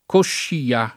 coscia [k0šša] s. f.; pl. cosce — e così pera coscia, pl. pere cosce (non pere coscia) — sim. il cogn. Coscia (alterato però in